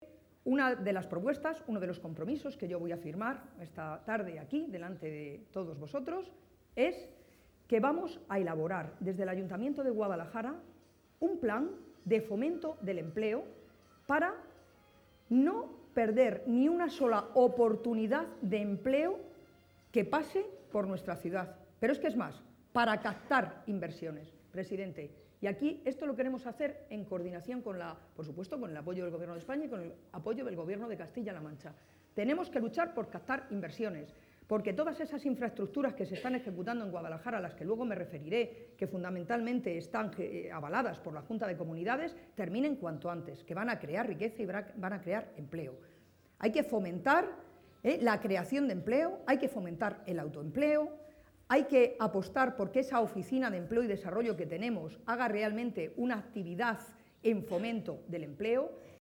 También ha valorado “el entusiasmo, la ilusión y las ideas tan claras” de Magdalena Valerio, a la que ha espetado “¡te veo como alcaldesa!” entre los aplausos de los asistentes, que abarrotaban el Salón de Actos del Conservatorio Provincial de Música.